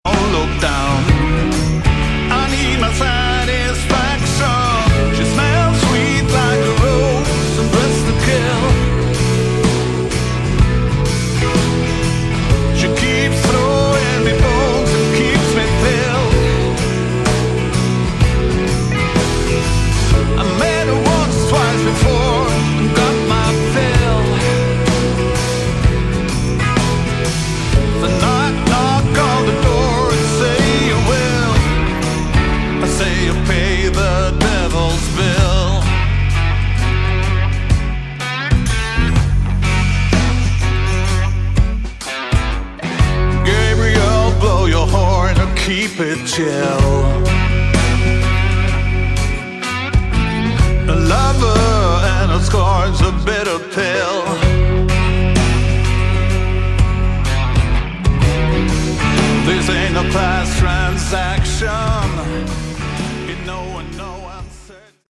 Category: Hard Rock
bass, keyboards, vocals
drums, percussion
guitar, vocals